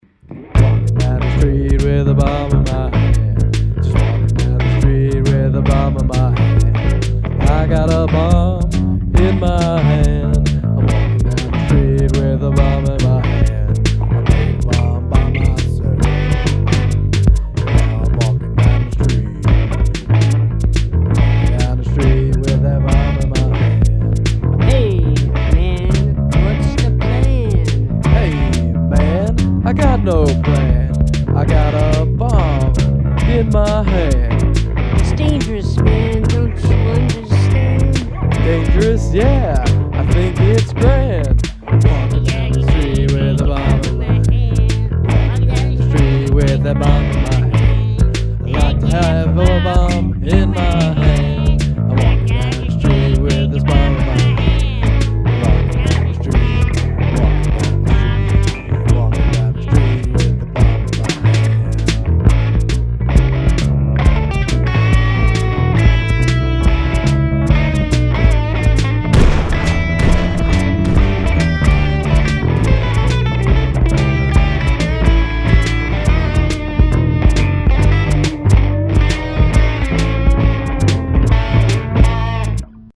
I started by playing the bass line. Then we added vocals.
playing sampletank drums using the keyboard for input. Than I added the guitar.
Short but sweet.
Another quirky tune set to instrumental backing drawn from the roots of rock. The cartoonish subject matter downplays its more sinister connotations: terrorism in the 21st century.
Really digging the guitar and bass sounds.
Kinda T-Rexy all around.
Anything with a chromatic melody tickles my funny bone.